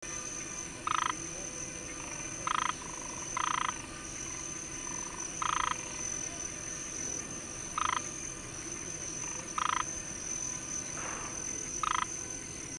frog calls in the same stream where we had been bathing.
8330Hypsiboas punctatus.mp3